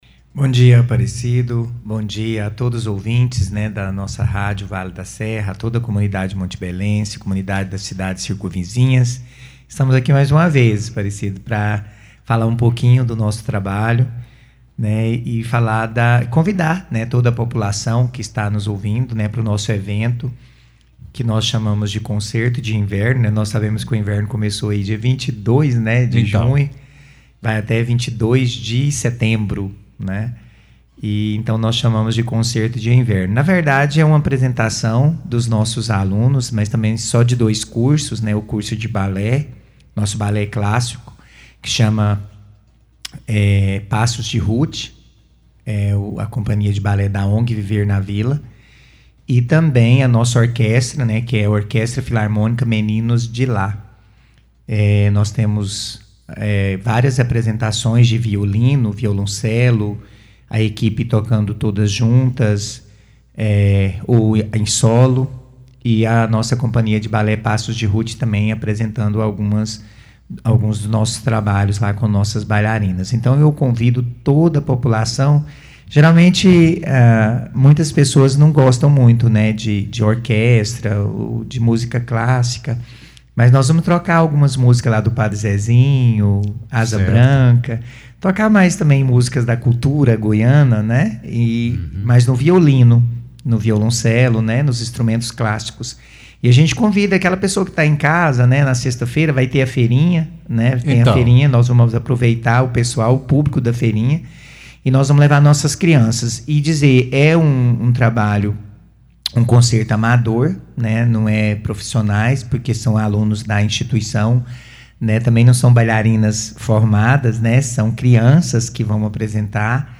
Saiba como será o evento e como foi o primeiro semestre para a ONG Viver na Vila, por meio das explicações do fundador da entidade, o vereador, professor doutor Erly Kyel.
FALA-DO-ERLY.mp3